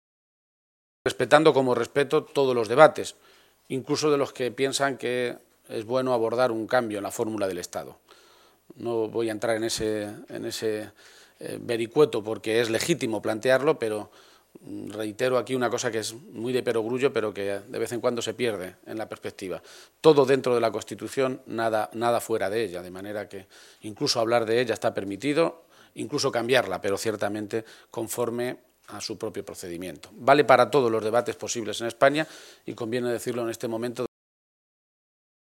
García-Page se pronunciaba de esta manera esta mañana en Toledo, a preguntas de los medios de comunicación.
Cortes de audio de la rueda de prensa